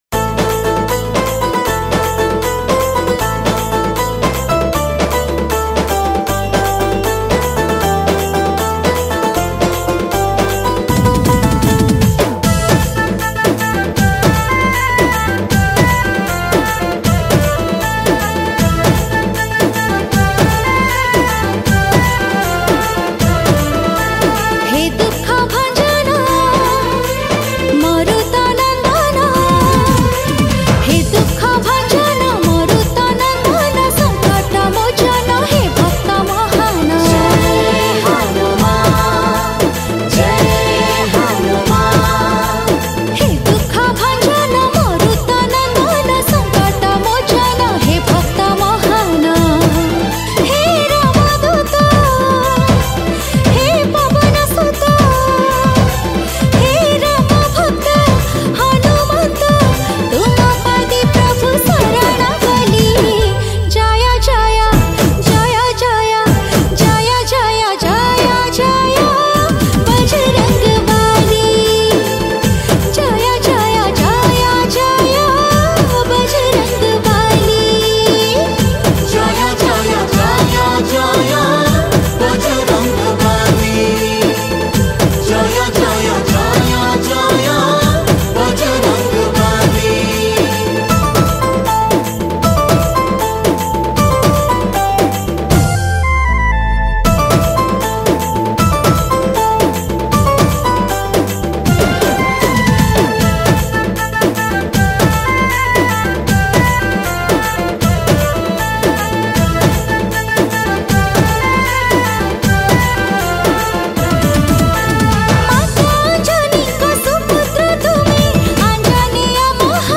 Pana Sankarati Special Bhajan Duration